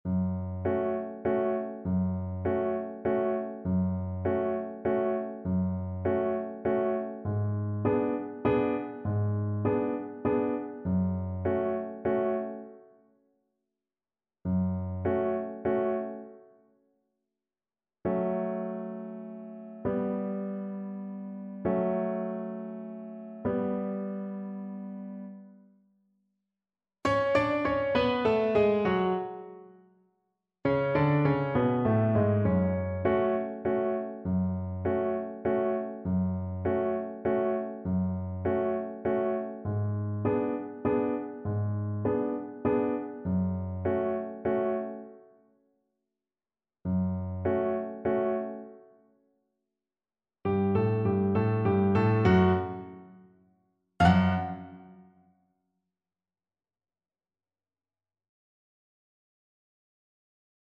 Violin version
Scarily
3/4 (View more 3/4 Music)
Film (View more Film Violin Music)